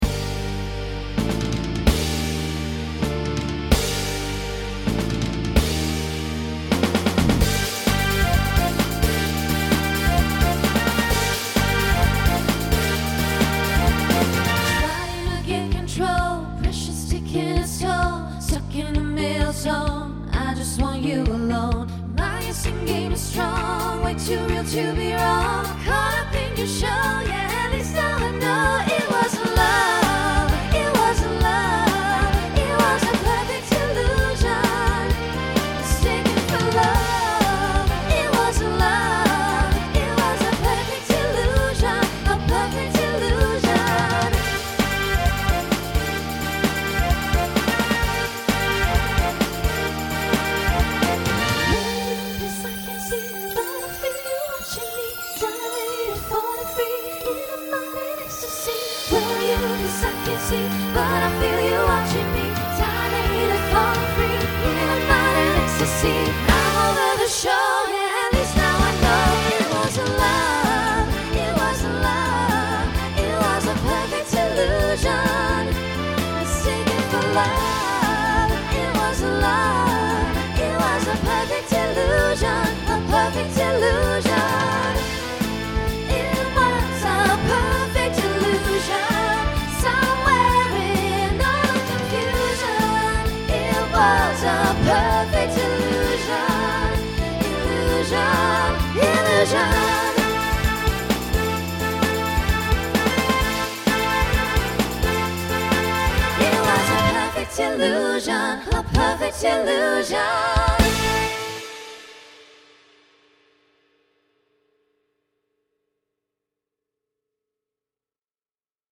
Voicing SSA Instrumental combo Genre Pop/Dance